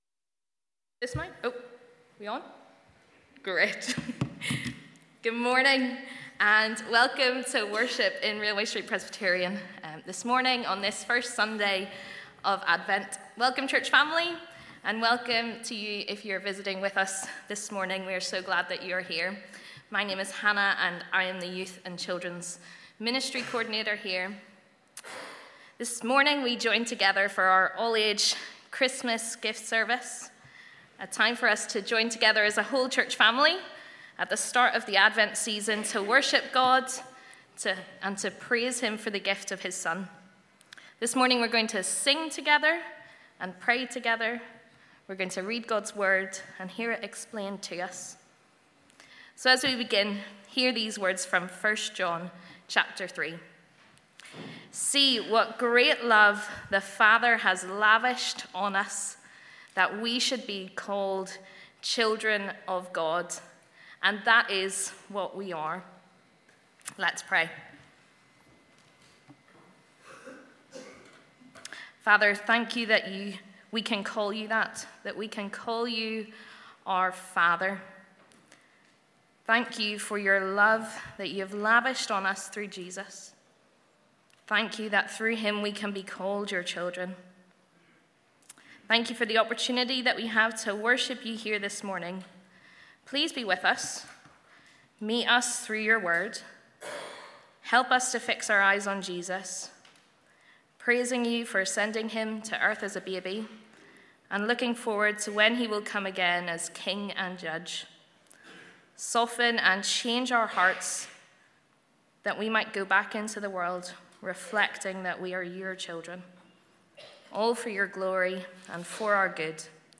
Welcome to our annual Christmas Gift Family Service.